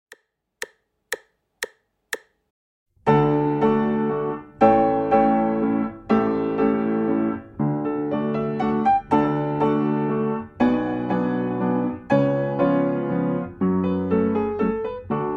II wersja – Tempo 120bmp (wersja bez metronomu)
Nagranie dokonane na pianinie Yamaha P2, strój 440Hz
piano